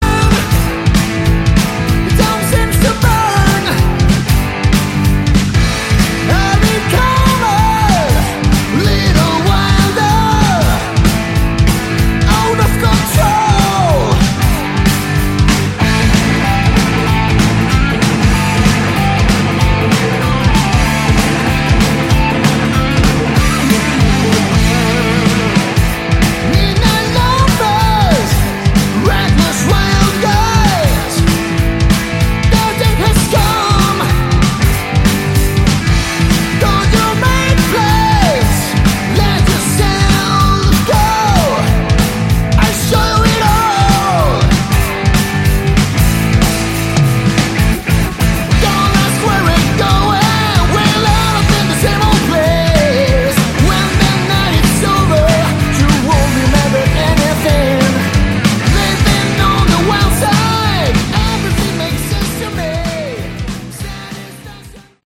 Category: Hard Rock
guitar
bass
lead vocals
drums